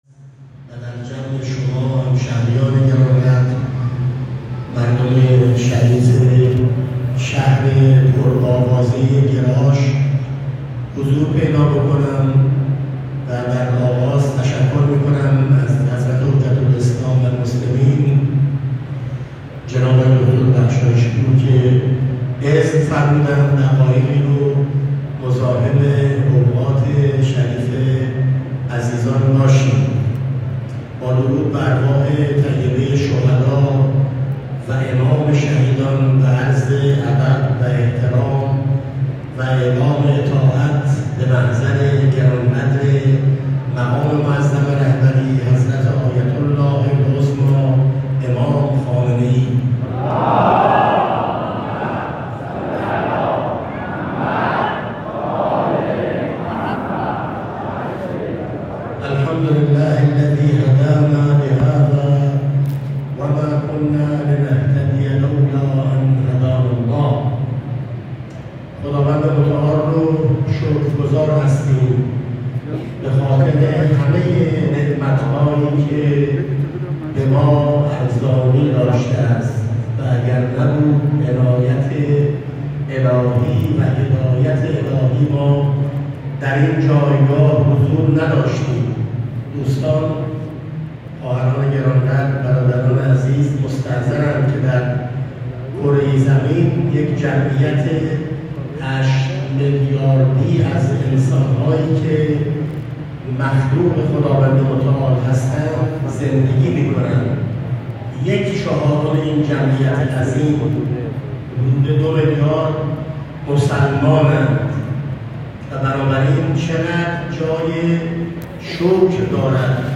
علاالدین بروجردی، نماینده مردم پنج شهرستان جنوب استان فارس در مجلس شورای اسلامی، امروز ۱۴ شهریورماه ۱۴۰۴ یک سخنرانی ۱۳ دقیقه‌ای در نماز جمعه گراش داشت و در کنار مناسبت‌های روز، به دو موضوع جاده چک‌چک و آبرسانی اشاره کرد.